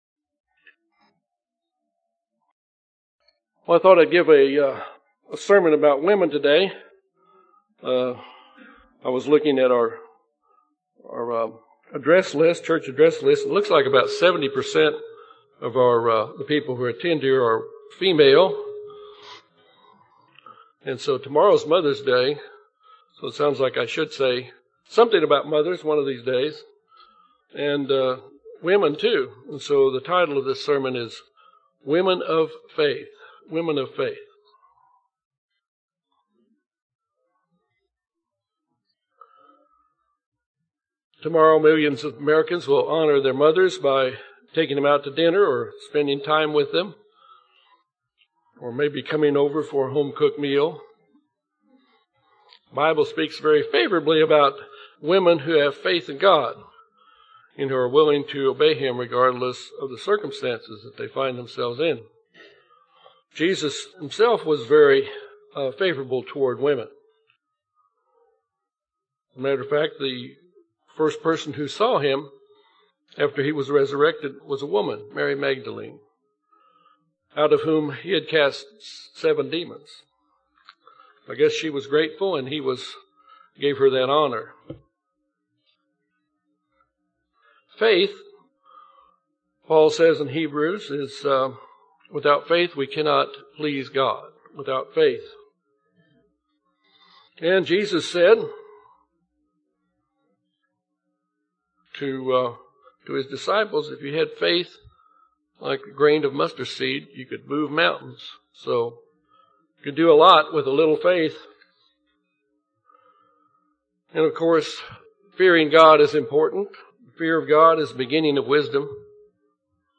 This sermon shows the importance of women of faith and discusses some examples.